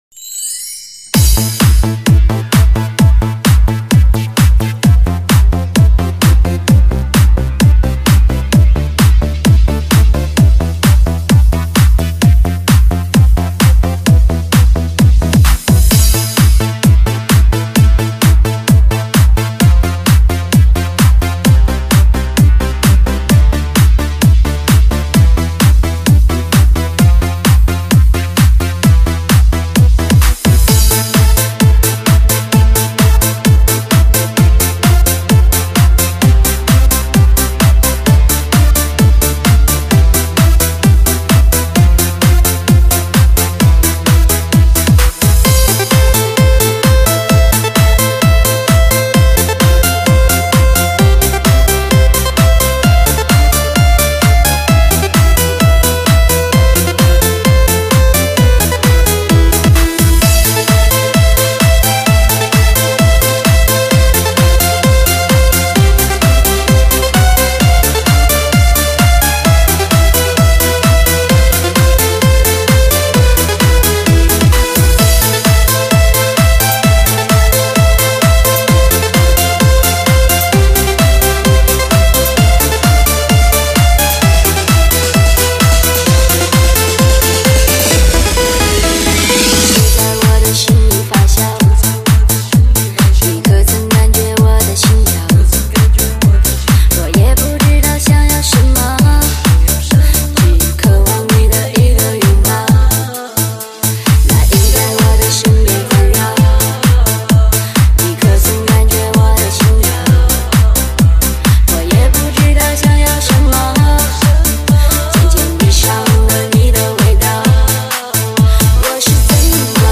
极品劲爆的士高
撼世巨作的最炫舞曲
体验激情一刻流行上口的旋律